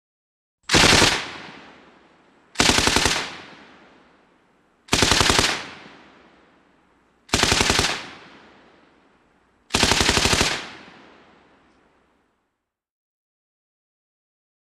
AutoMachGunBurst PE706102
WEAPONS - MACHINE GUNS HEAVY SQUAD AUTOMATIC WEAPON: EXT: Short bursts & single shots, medium distance with echo.